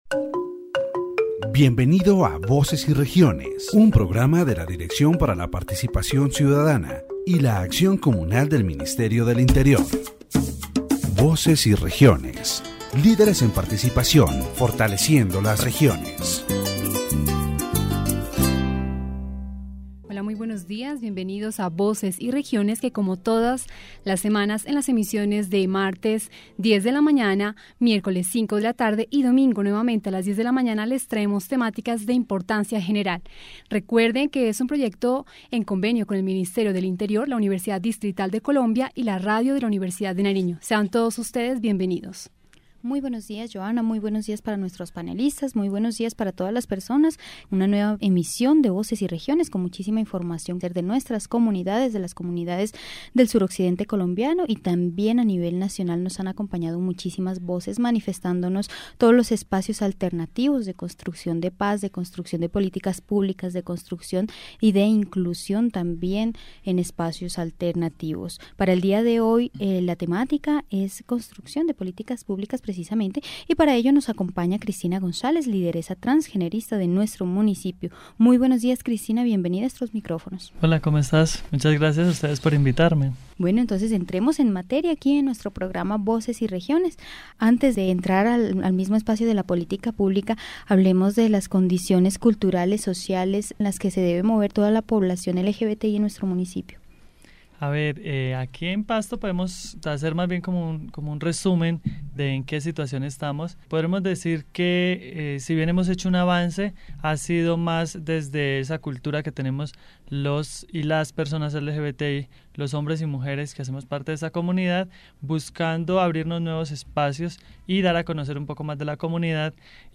Voices and Regions is a radio program from the Directorate for Citizen Participation and Communal Action of the Ministry of the Interior, which is broadcast weekly at different times. In this broadcast, the central theme is the construction of public policies, especially in relation to the LGBTI community in Pasto and other regions of southwestern Colombia.